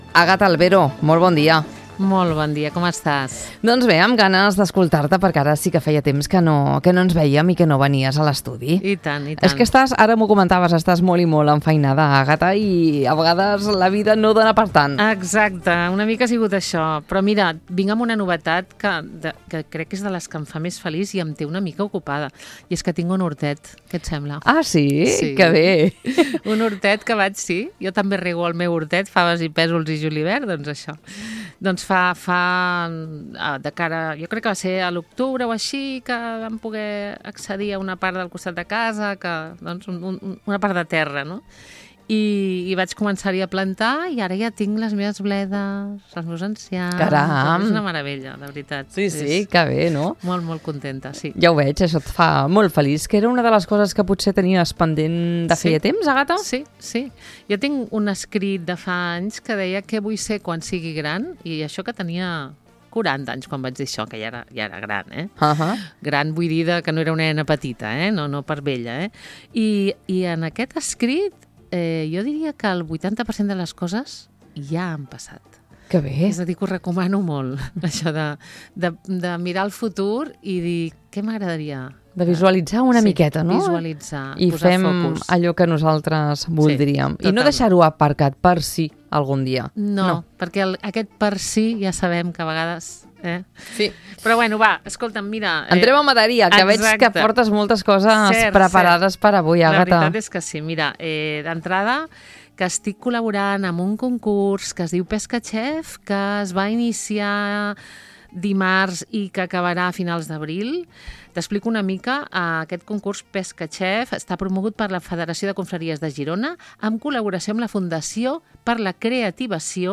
Durant la conversa també hem repassat altres fires i activitats gastronòmiques que tindran lloc a la comarca durant les properes setmanes, consolidant l’Alt Empordà com un territori amb una gran riquesa culinària i gastronòmica.